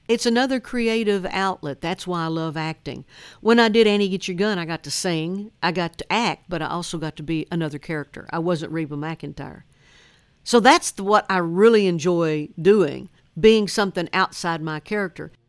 Reba McEntire says acting is another creative outlet for her.